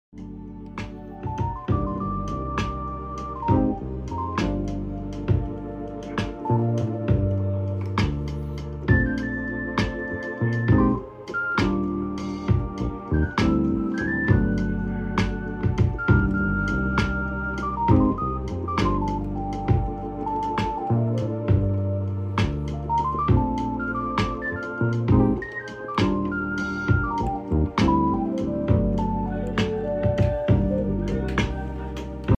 Composition and improvisation ✨✨